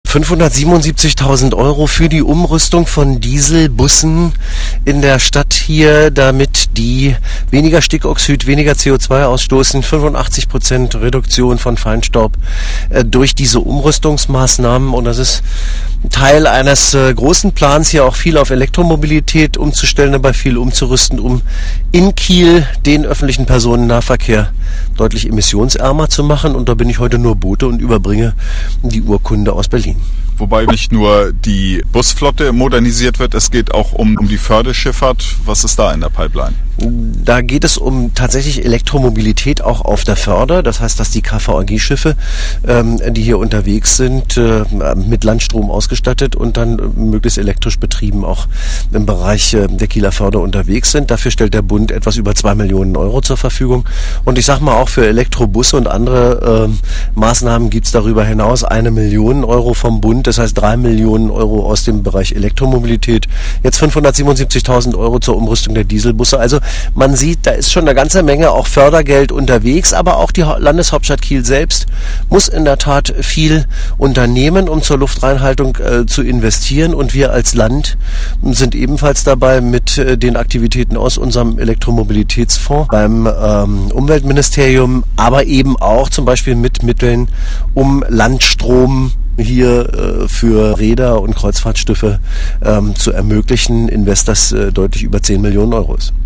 buBuchholz sagte bei der Übergabe der Förderurkunde weiter